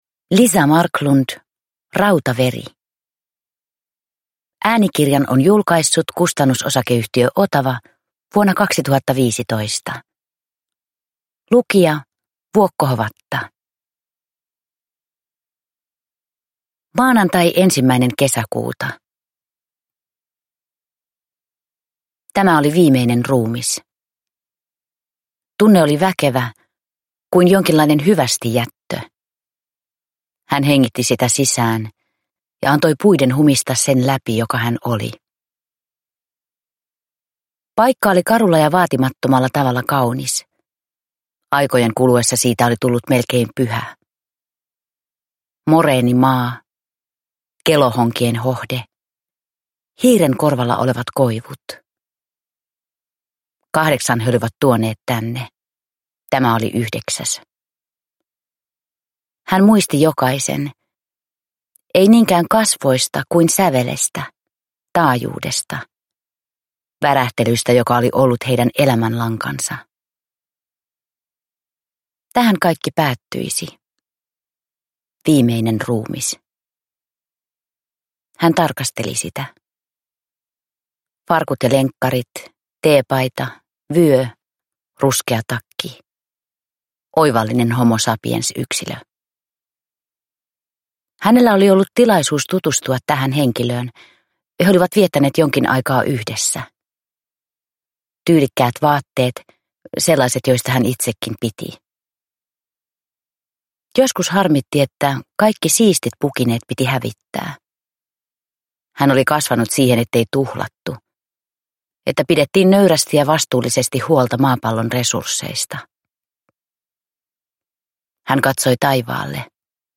Rautaveri – Ljudbok – Laddas ner